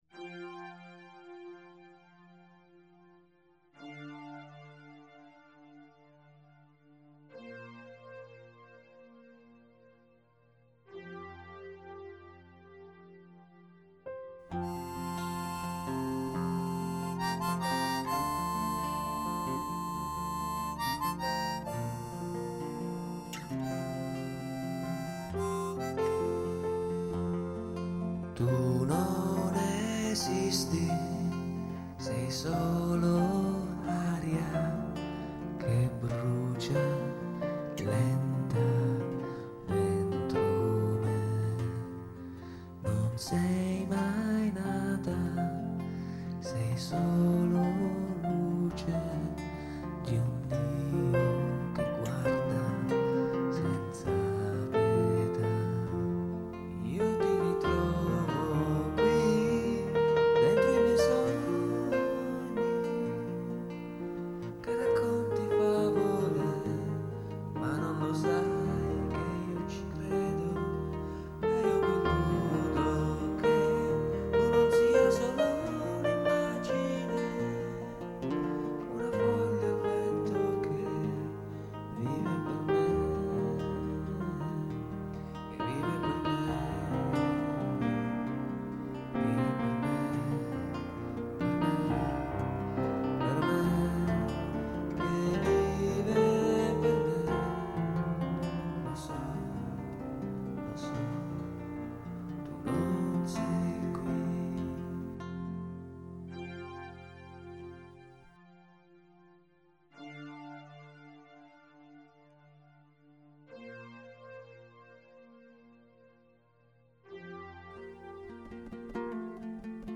Piano e voce
Armonica
Chitarra